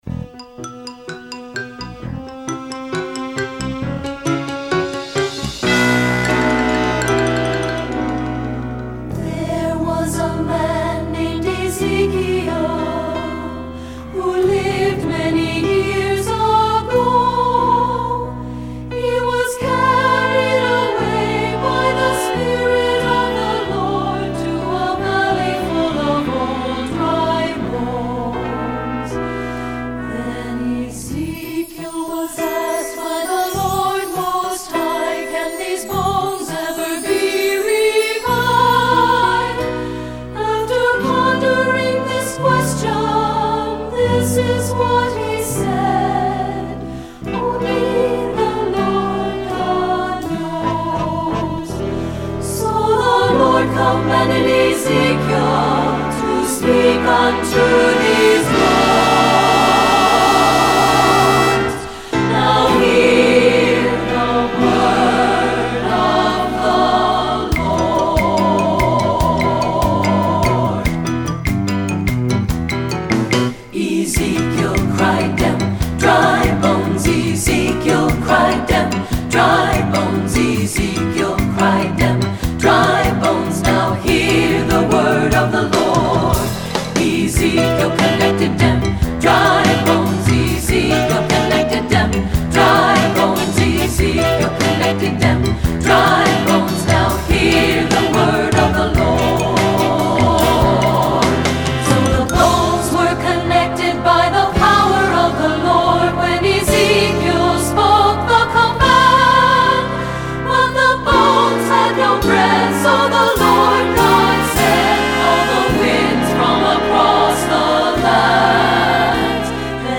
Composer: Spiritual
Voicing: SSAA